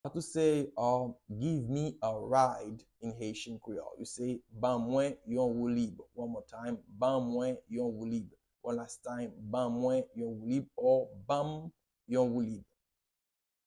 How to say “Give me a ride” in Haitian Creole - “Ban mwen yon woulib” pronunciation by a native Haitian Teacher
“Ban mwen yon woulib” Pronunciation in Haitian Creole by a native Haitian can be heard in the audio here or in the video below: